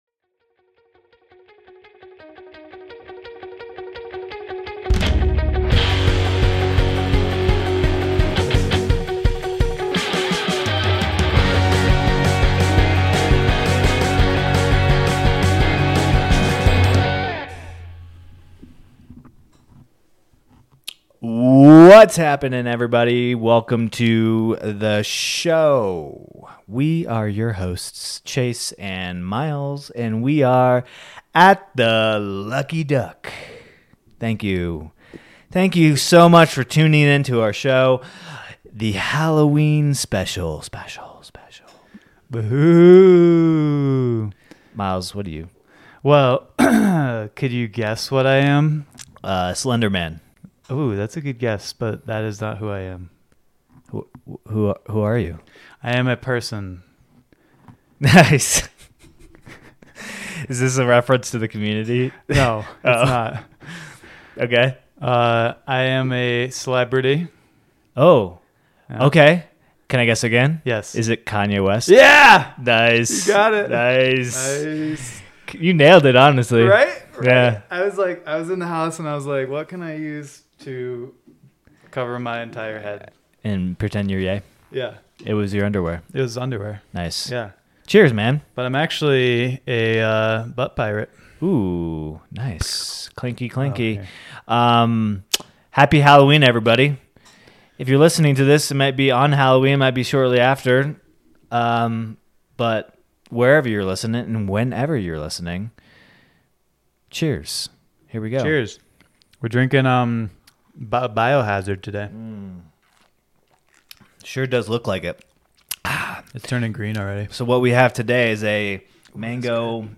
share conversation over cocktails and Celebrate Halloween 2024!.